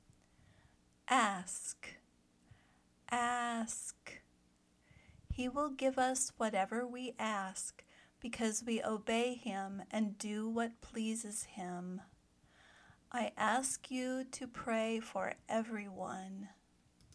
/æsk/ (verb)